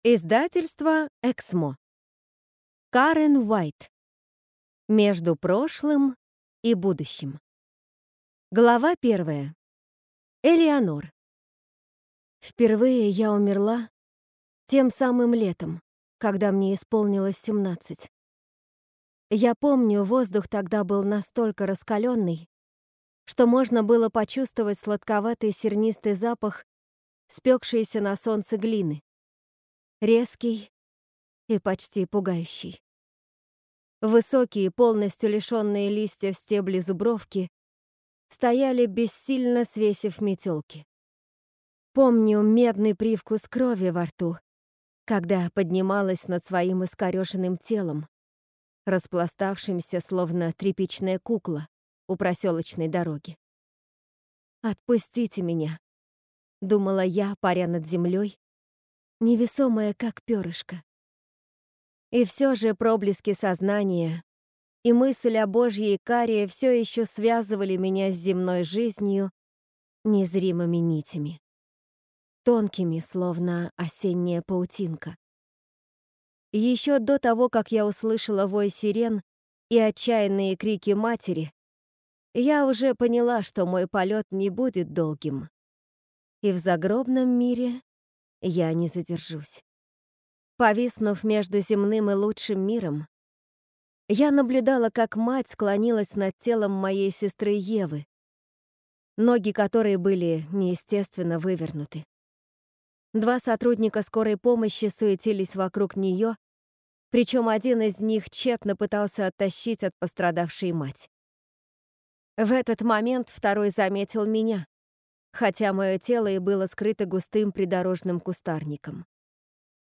Аудиокнига Между прошлым и будущим | Библиотека аудиокниг